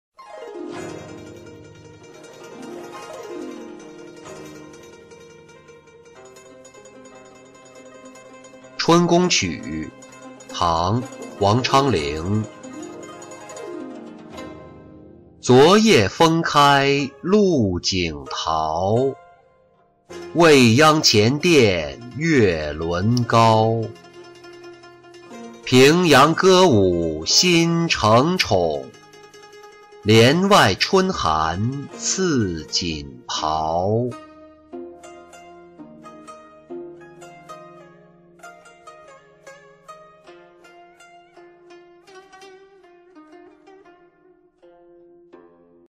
春宫曲-音频朗读